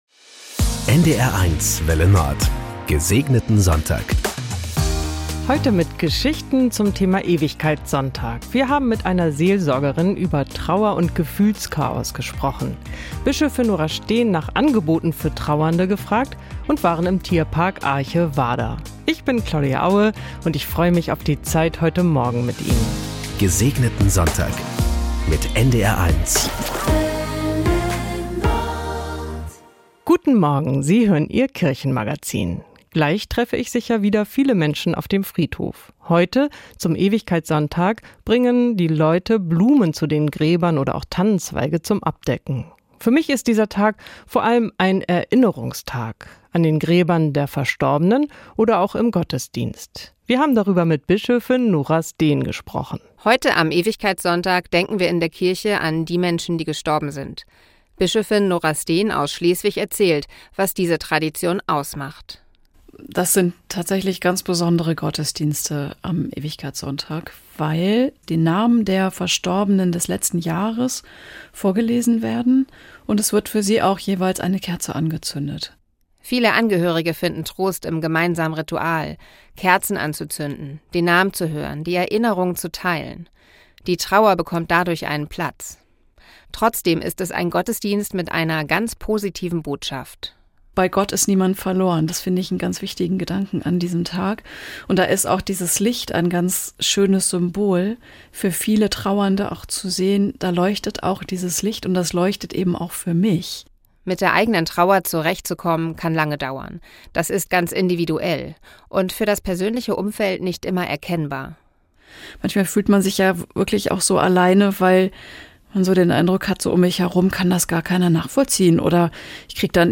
Im Kirchenmagazin am Ewigkeitssonntag geht es um die Trauer für ungeliebte Angehörige, die Arche Warder und ein Interview mit Bischöfin Nora Steen.